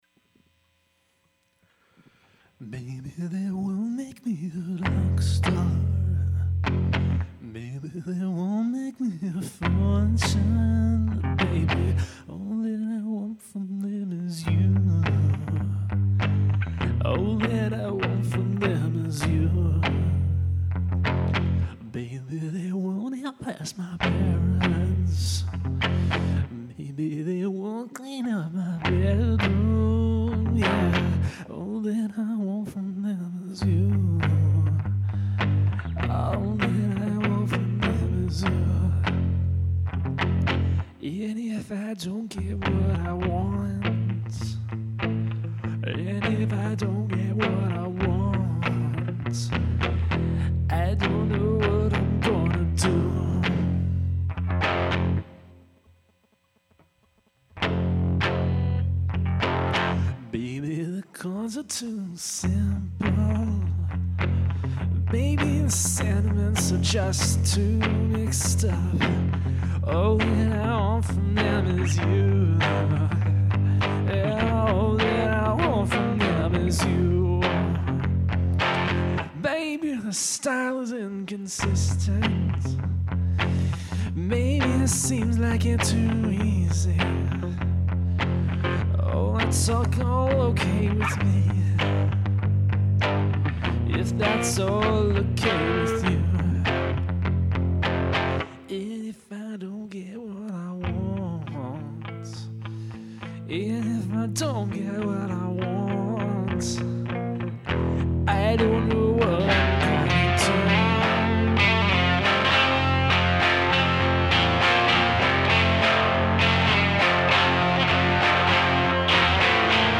Because it has a sweet guitar solo.
Which is not to say I don't like it - it's simple, and the lyrics are a lot more emotionally straightforward than what I've been doing lately.
Nice song and it was a sweet guitar solo!